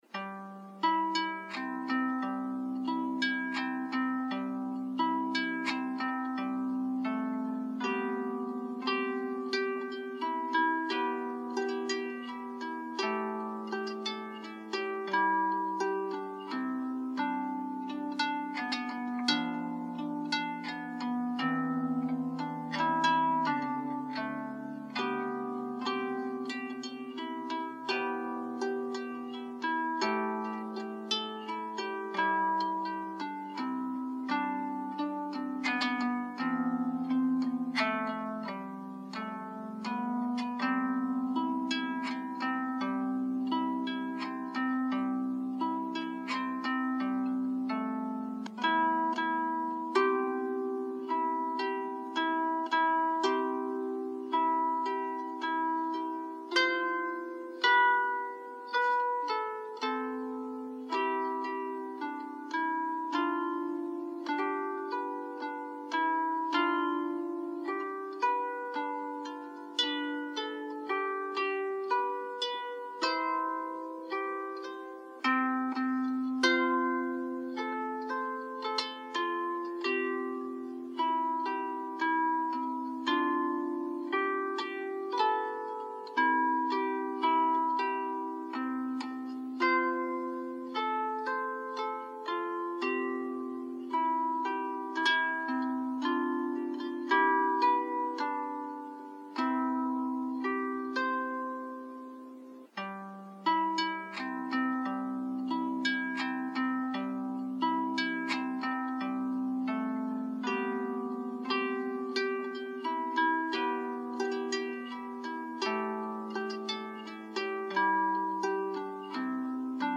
So, here are my original, “Lullaby of the Ages,” and the Brahms lullaby played on an honest-to-goodness double strung harp!
I hope you enjoy this medley as much as I love playing and hearing it!